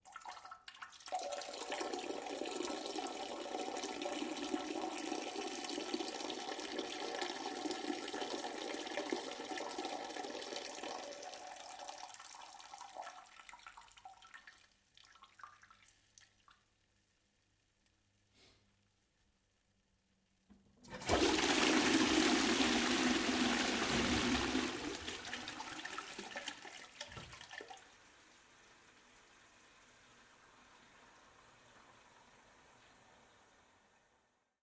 Звуки мочеиспускания
Мужчина пописал в унитаз и смыл за собой